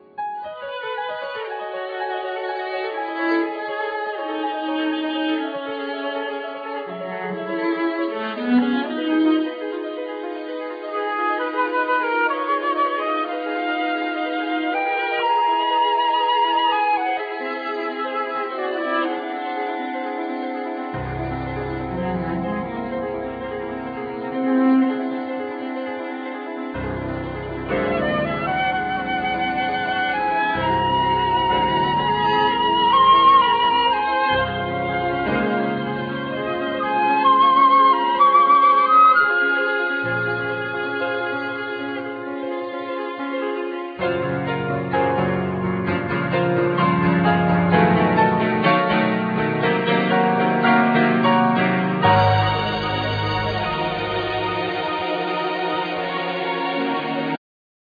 Flute,EW5
Guitars
Cello
Keyboards,Bass,Percussions
Voices